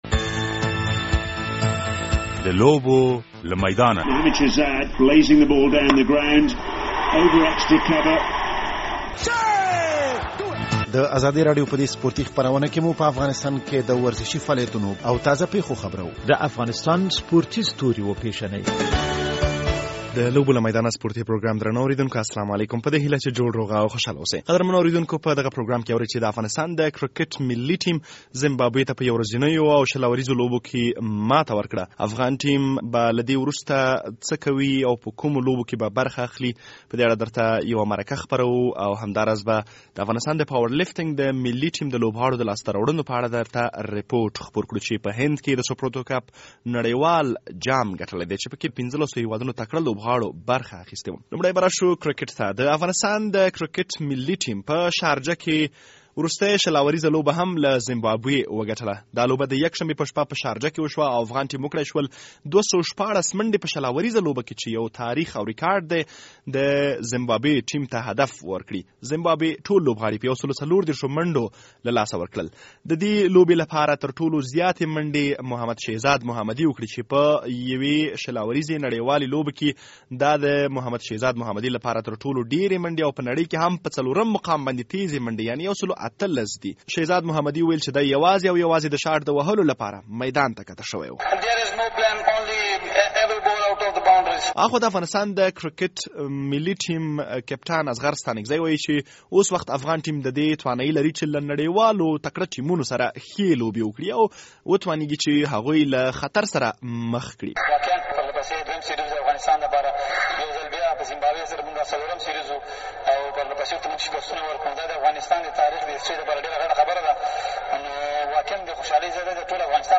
په دغه پروګرام کې د افغانستان د کرکټ د ملي ټیم د وروستیو اتلولیو او د افغان پاورلفترانو د نړېوالې اتلولۍ په اړه مرکه او رپوټ اورئ.